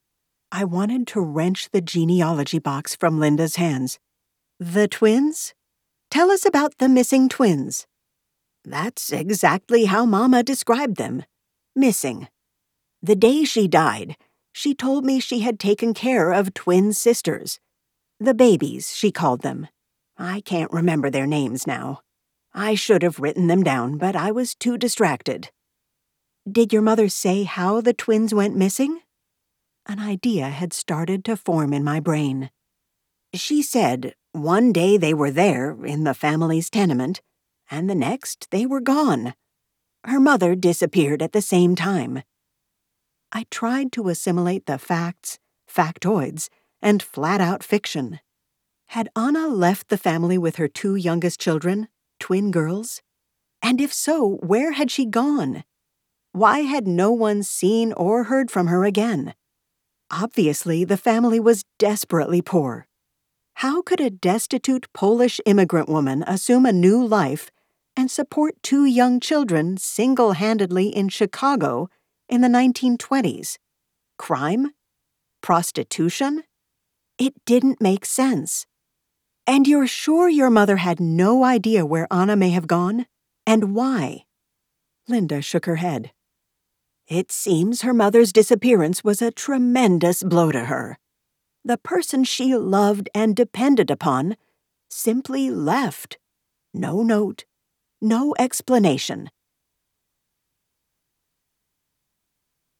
Eighteenth and Western is upmarket book club fiction. It tells the stories of three women, each in a different era-1920, 1970, and present-day-struggling for rights and fulfillment.